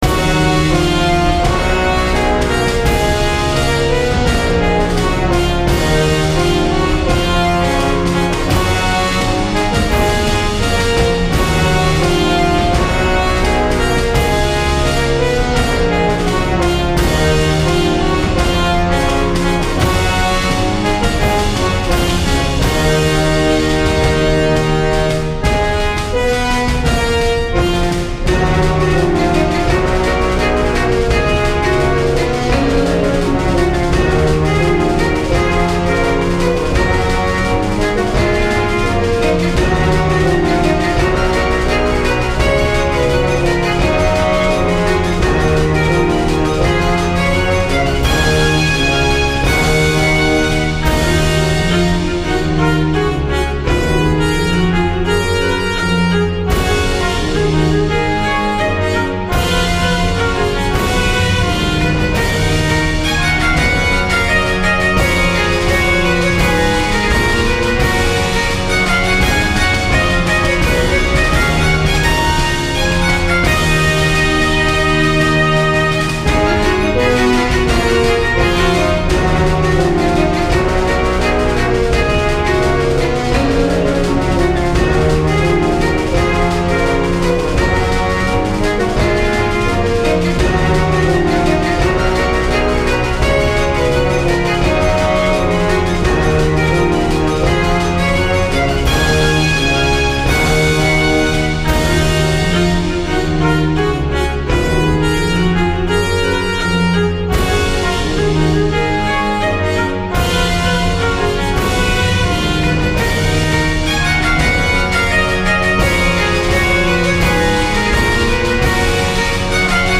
オーケストラVer.
RPGやアクションゲームなどの通常戦闘シーンを想定して制作した、テンポ感のある戦闘用BGMです。
ジャンル： ゲーム音楽／バトルBGM／ファンタジー／アクション
雰囲気： テンポ感／疾走感／緊張感／軽快／前向き